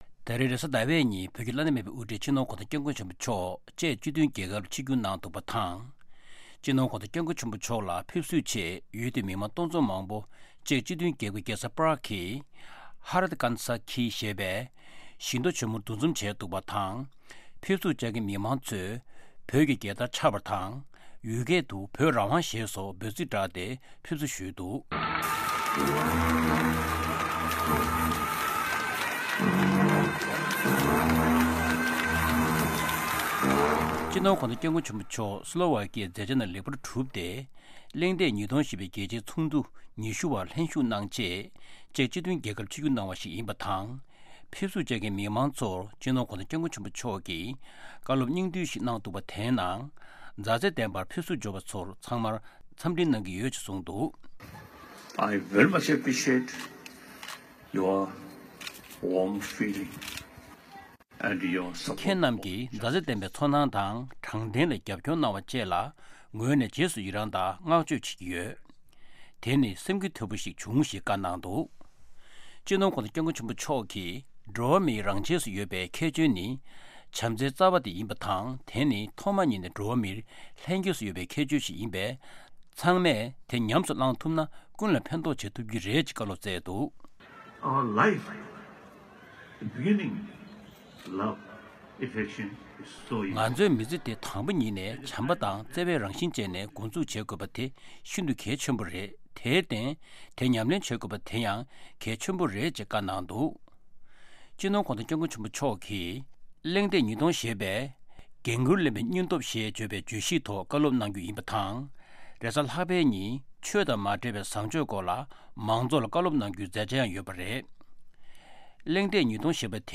ད་རིང་རེས་གཟའ་ཟླ་བའི་ཉིན་བོད་ཀྱི་བླ་ན་མེད་པའི་དབུ་ཁྲིད་སྤྱི་ནོར་༧གོང་ས་༧སྐྱབས་མགོན་ཆེན་པོ་མཆོག་ཅེག་སྤྱི་མཐུན་རྒྱལ་ཁབ་ལ་ཆིབས་བསྒྱུར་གནང་འདུག་པ་དང་། སྤྱི་ནོར་༧གོང་ས་༧སྐྱབས་མགོན་ཆེན་པོ་མཆོག་ས་གནས་སུ་ཞབས་སོར་བཀོད་སྐབས་་ཡུལ་དེའི་མི་མང་སྟོང་ཚོང་མང་པོ་ཅེག་་སྤྱི་མཐུན་རྒྱལ་ཁབ་ཀྱི་རྒྱལ་ས་པརཀ་གི་ཧ་རཏ་ཀན་ས་ཀི་ཞེས་པའི་བཞི་མདོ་ཆེ་མོར་འདུ་འཛོམས་ནས་ཕེབས་བསུ་ཞུས་འདུག ཕེབས་བསུར་བཅར་མཁན་མི་མང་ཚོས་བོད་ཀྱི་རྒྱལ་དར་འཕྱར་བ་དང་ཡུལ་སྐད་དུ་བོད་རང་དབང་ཞེས་སོགས་འབོད་ཚིག་བསྒྲགས་ཏེ་ཕེབས་བསུ་ཞུས་འདུག་ཅིང་། སྤྱི་ནོར་༧གོང་ས་༧སྐྱབས་མགོན་ཆེན་པོ་མཆོག་སི་ལོ་ཝ་ཀི་ཡའི་མཛད་འཆར་རྣམས་ལེགས་པར་གྲུབ་སྟེ། གླེང་སྟེགས་ཉིས་སྟོང་ཞེས་པའི་རྒྱལ་སྤྱིའི་ཚོགས་འདུ་ཉི་ཤུ་བར་ལྷན་བཞུགས་གནང་ཆེད་ཅེག་སྤྱི་མཐུན་རྒྱལ་ཁབ་ལ་ཆིབས་བསྒྱུར་གནང་བ་ཞིག་ཡིན་པ་དང་། ཕེབས་བསུར་བཅར་མཁན་མི་མང་ཚོར་སྤྱི་ནོར་༧གོང་ས་༧སྐྱབས་མགོན་ཆེན་པོ་མཆོག་གིས་བཀའ་སློབ་སྙིང་བསྡུས་ཤིག་གནང་བའི ཁྱེད་རྣམས་ཀྱིས་མཛའ་བརྩེ་ལྡན་པའི་ཚོར་སྣང་དང་དྲང་བདེན་ལ་རྒྱབ་སྐྱོར་གནང་བ་བཅས་ལ་རྗེས་སུ་ཡི་རངས་དང་བསྔགས་བརྗོད་གནང་གི་ཡོད། དེ་ནི་སེམས་འགུལ་ཐེབས་པོ་ཞིག་བྱུང་ཞེས་གསུངས་འདུག